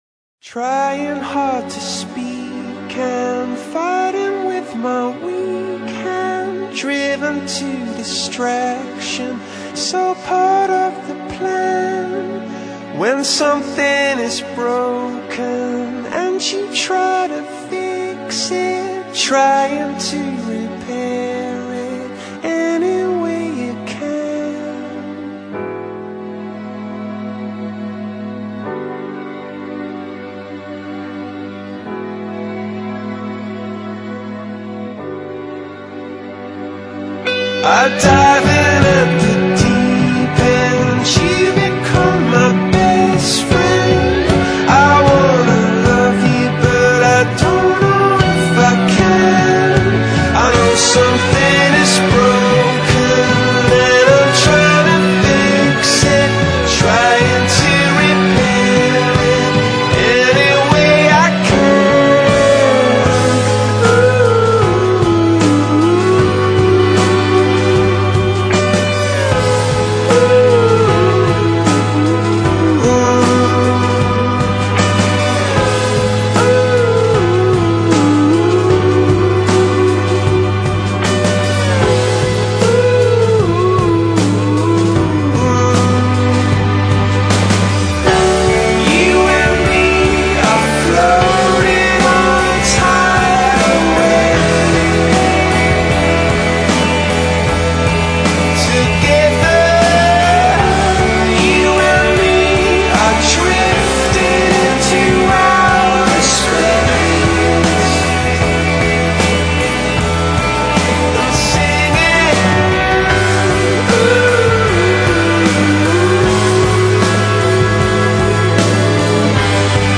带着一种深情，一种伤感，一种无奈，一种性感，像一个历经沧桑的男人正对你暗带悲泣地倾诉和忏悔